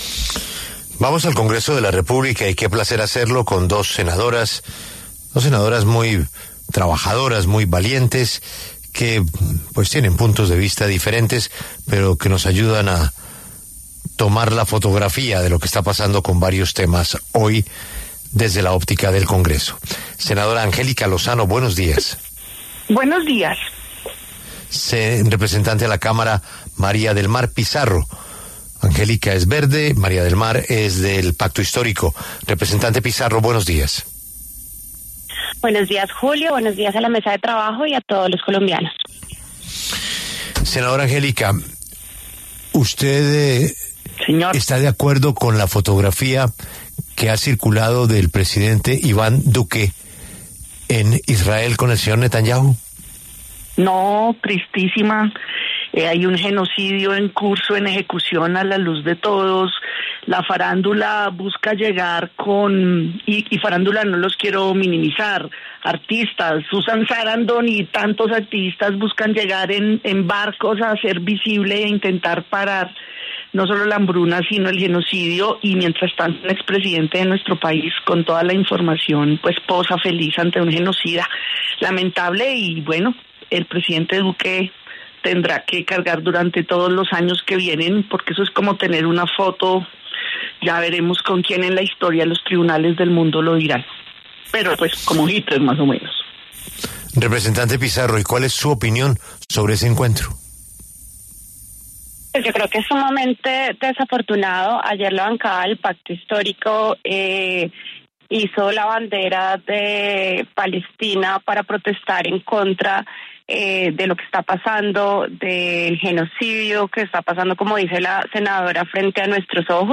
La senadora Angélica Lozano, de la Alianza Verde, y la representante María del Mar Pizarro, del Pacto Historio, pasaron por los micrófonos de La W.